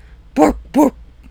ARCANINE.wav